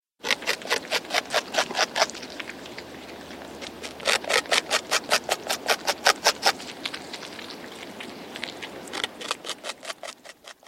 Beaver Eating-sound-HIingtone
beaver-eating.mp3